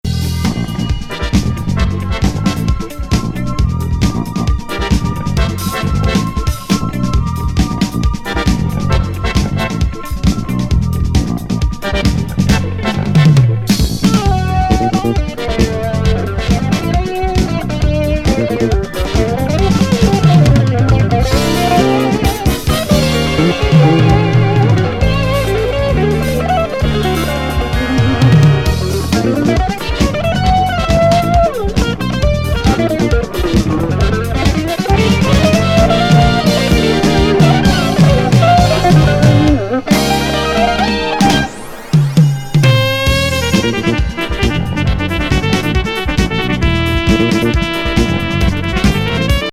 スペーシー・ムーグ・シンセ・ファンキー・カバー作!